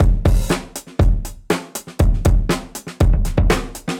Index of /musicradar/dusty-funk-samples/Beats/120bpm